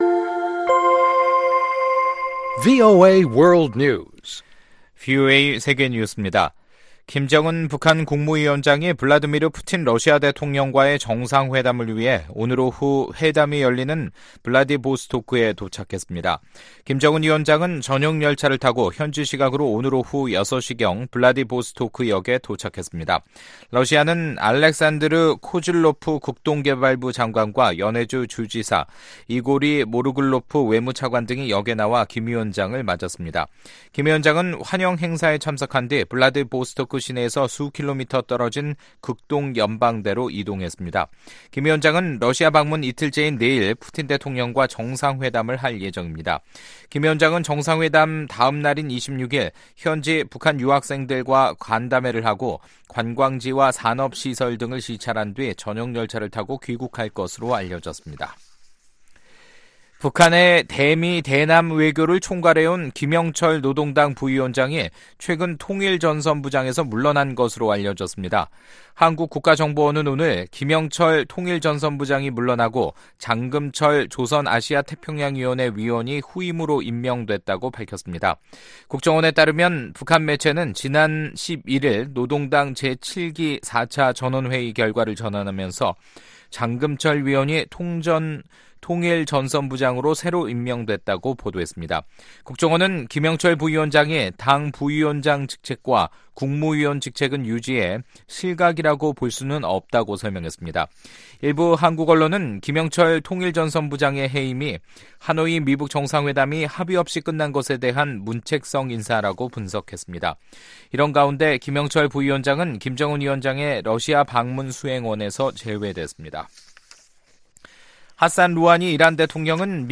VOA 한국어 간판 뉴스 프로그램 '뉴스 투데이', 2019년 4월 24일 2부 방송입니다. 김정은 북한 국무위원장이 푸틴 러시아 대통령과의 정상회담을 위해 현지시간 24일 오후 6시게 블라디보스토크에 도착했습니다. 일본 정부가 새 외교청서에서 대북 ‘최대압박’이란 표현을 삭제하는 등 북한에 유화적인 자세를 보였습니다.